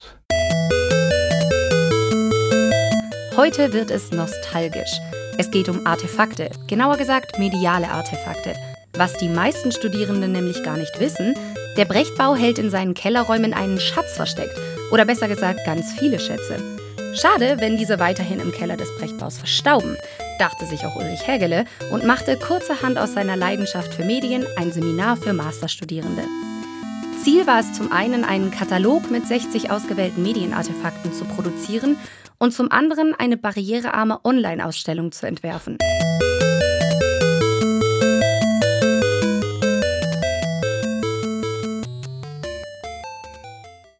Mediale Artefakte - Studiogespräch zur Ausstellung (499)
499_Ton-Teaser.mp3